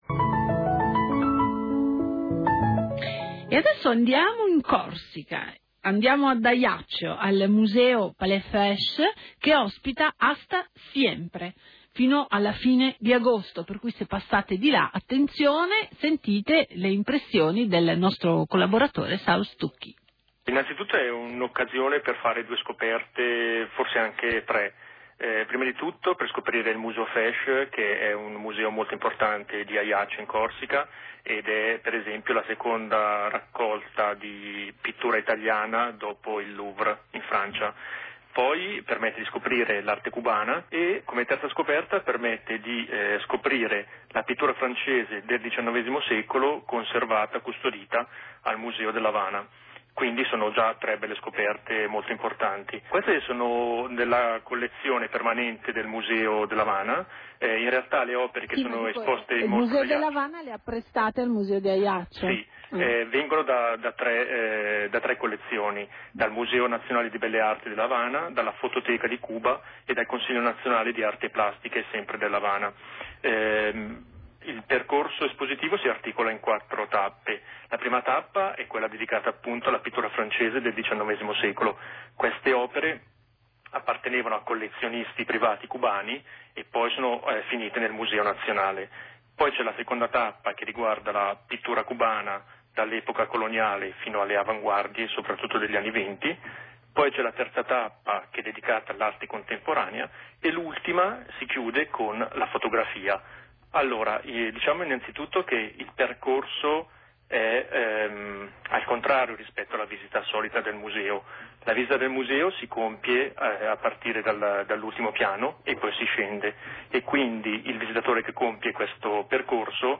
Qui sotto trovate il podcast della trasmissione I Girasoli di Radio Popolare, condotta in studio